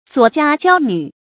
左家娇女 zuǒ jiā jiāo nǚ
左家娇女发音